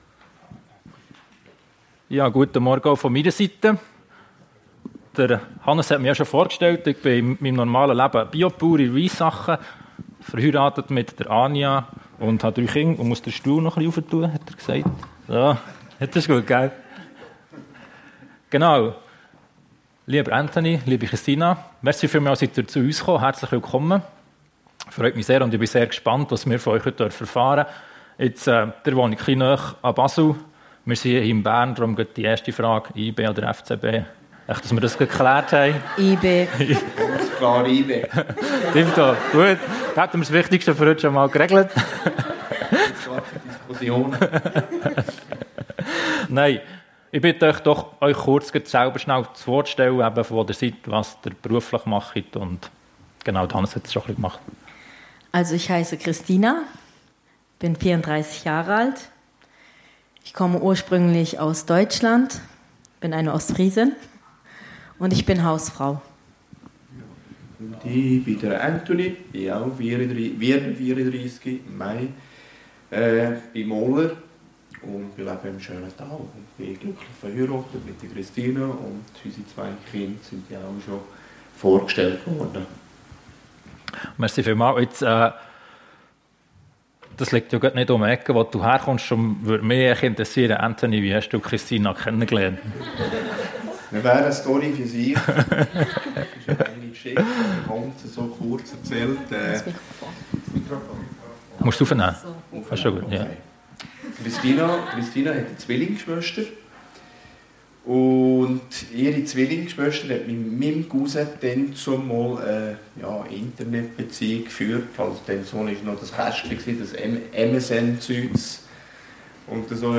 Talkgottesdienst